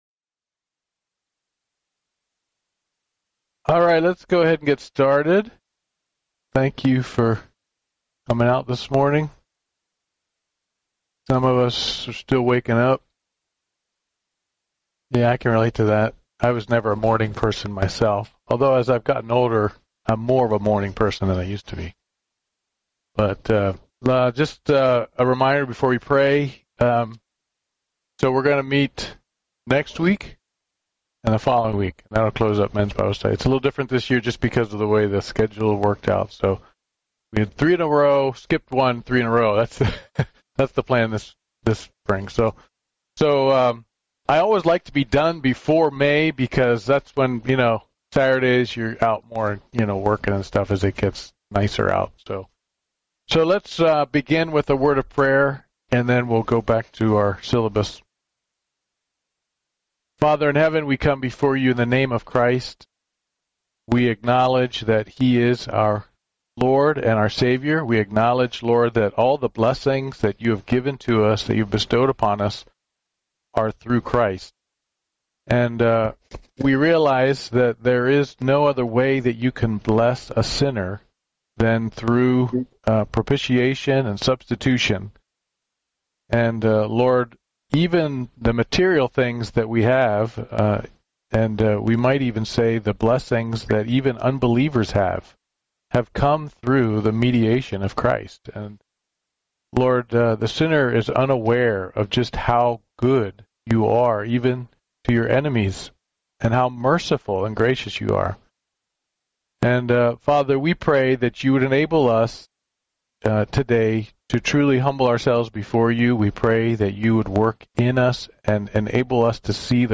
Descriptions of Christ Service Type: Men's Bible Study « Sorrento